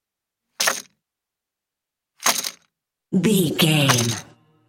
Casino 10 chips table x3
Sound Effects
foley